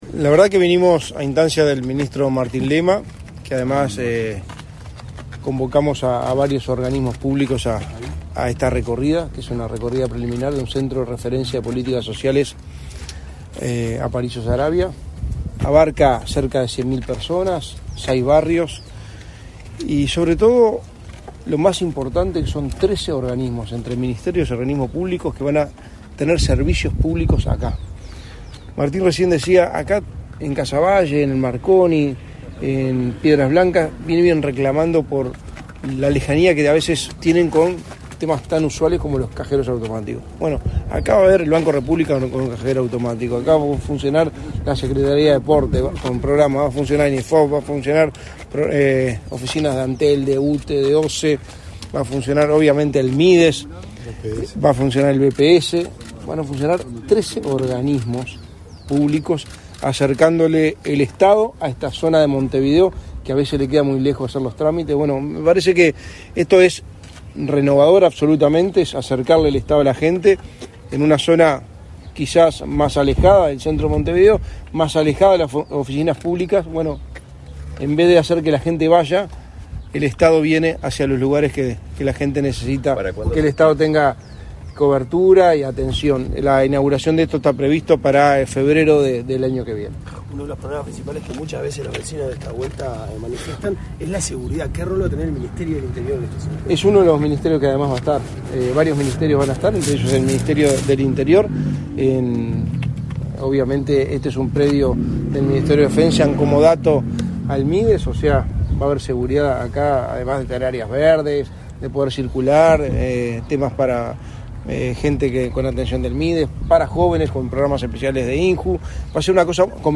Declaraciones a la prensa del secretario de la Presidencia, Álvaro Delgado, y el ministro Martín Lema
Declaraciones a la prensa del secretario de la Presidencia, Álvaro Delgado, y el ministro Martín Lema 06/10/2023 Compartir Facebook X Copiar enlace WhatsApp LinkedIn Tras participar en la recorrida por las obras del Centro de Referencia de Políticas Sociales Aparicio Saravia, este 6 de octubre, el secretario de la Presidencia, Álvaro Delgado, y el ministro de Desarrollo Social, Martín Lema, realizaron declaraciones a la prensa.